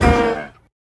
Minecraft Version Minecraft Version snapshot Latest Release | Latest Snapshot snapshot / assets / minecraft / sounds / mob / sniffer / hurt1.ogg Compare With Compare With Latest Release | Latest Snapshot
hurt1.ogg